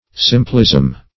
simplism - definition of simplism - synonyms, pronunciation, spelling from Free Dictionary